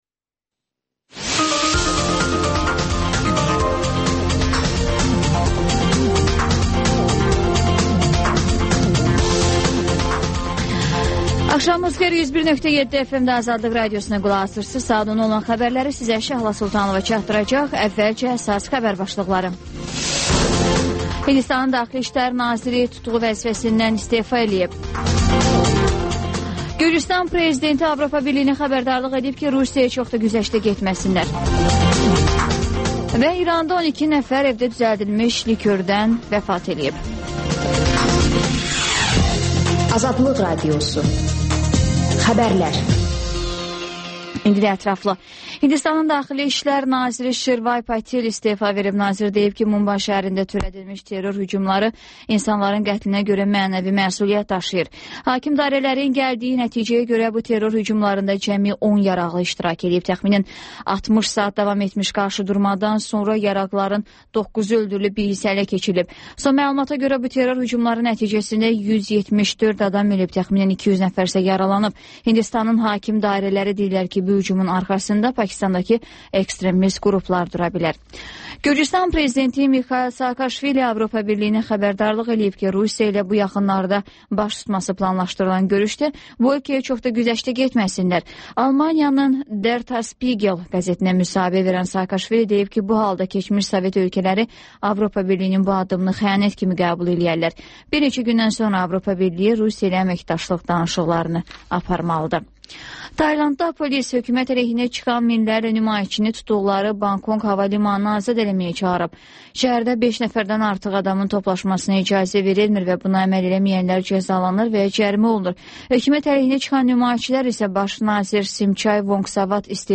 Xəbərlər və ŞƏFFAFLIQ: Korrupsiya barədə xüsusi veriliş